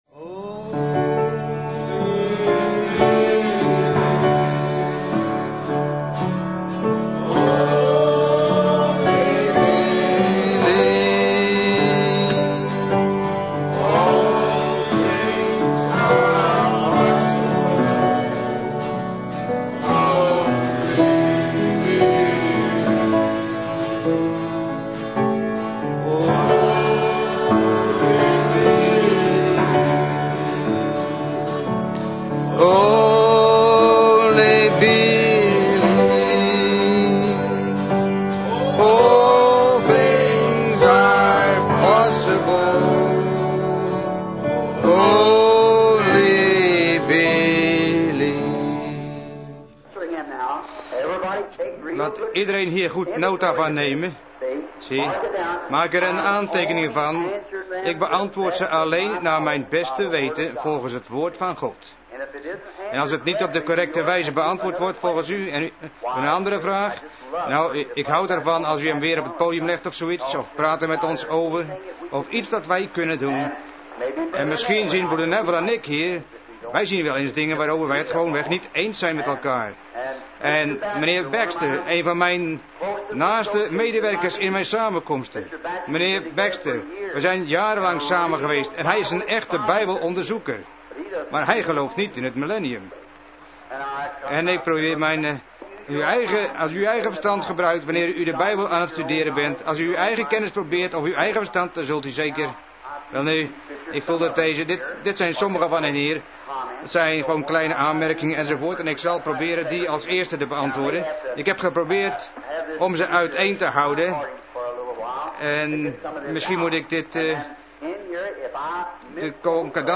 Vertaalde prediking "Questions and answers" door William Marrion Branham te Branham Tabernacle, Jeffersonville, Indiana, USA, op zaterdag 15 mei 1954